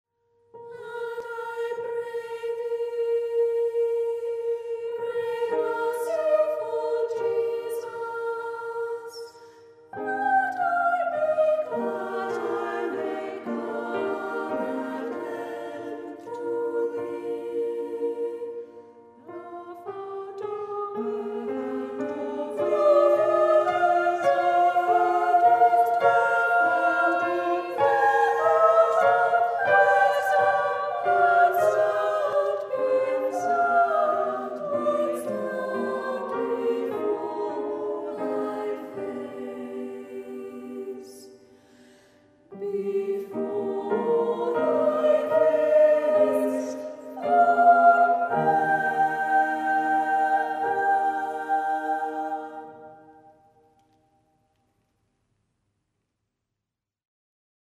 Three-part